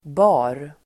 Uttal: [ba:r]